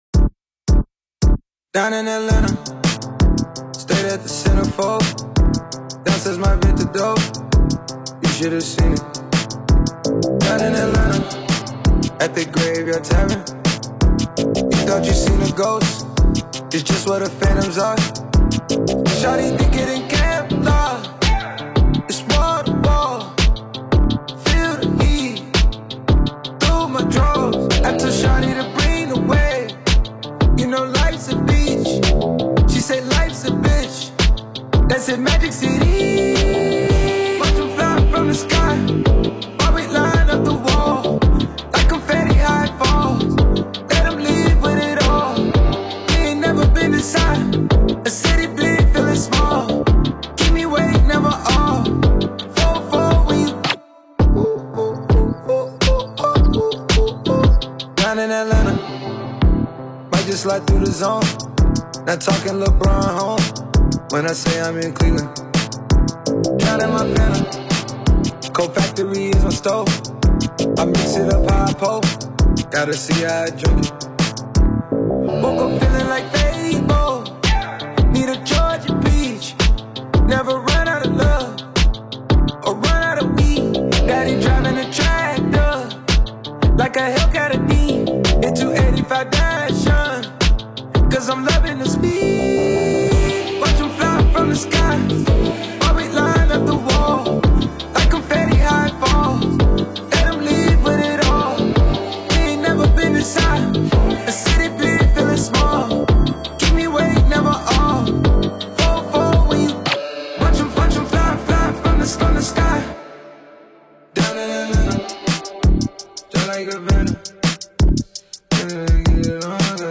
سبک هیپ هاپ